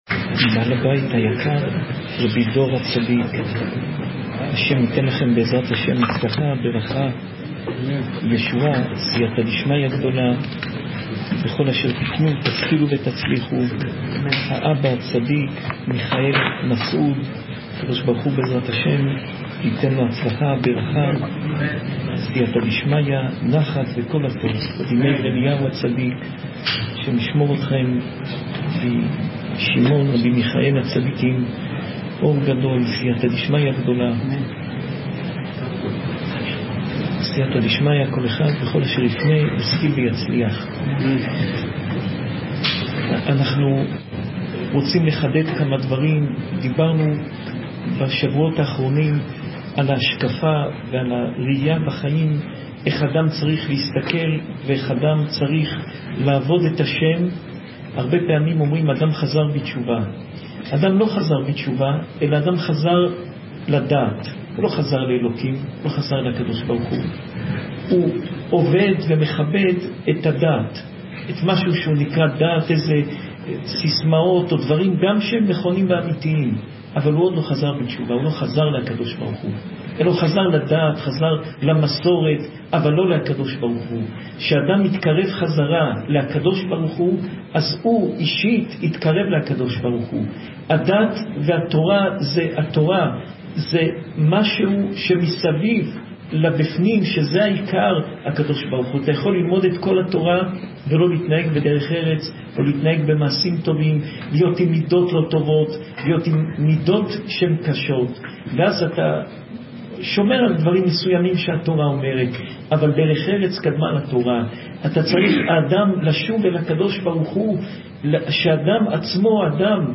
שעורי תורה מפי הרב יאשיהו יוסף פינטו